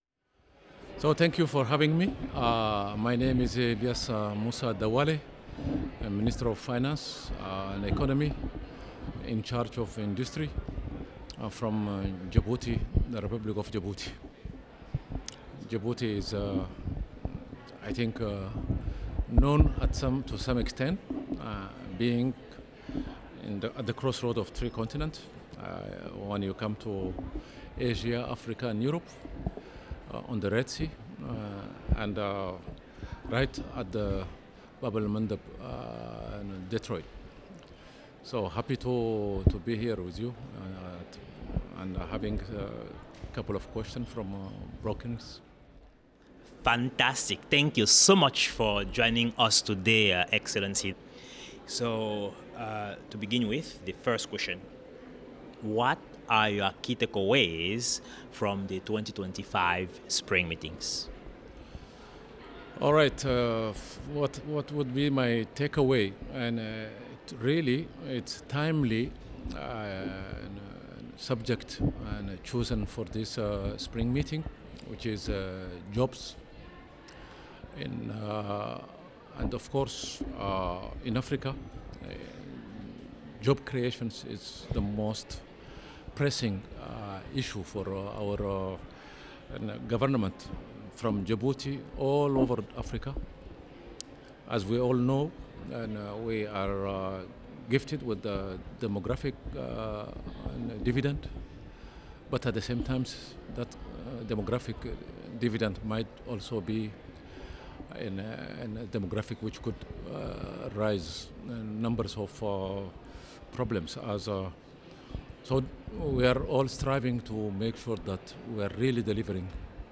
Foresight Africa podcast at the 2025 World Bank/IMF Spring Meetings
Ilyas Moussa Dawaleh Minister of Finance and Economy - Republic of Djibouti